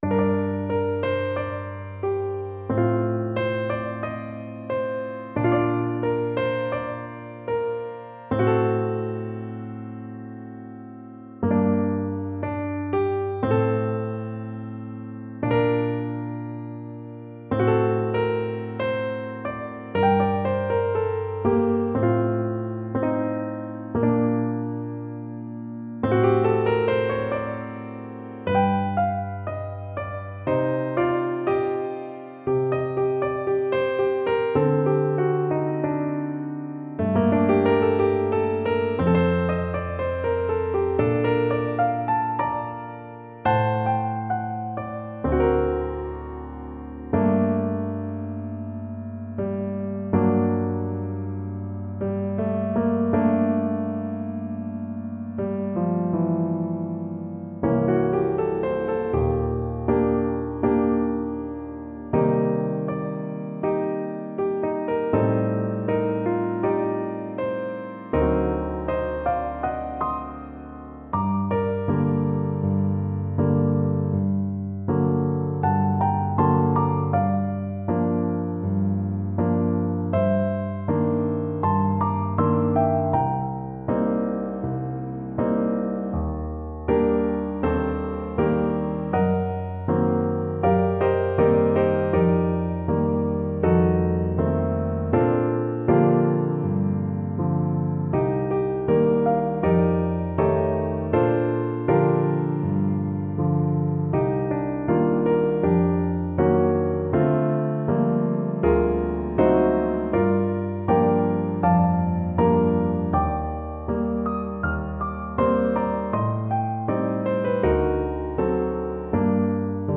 in Gm (original)
piano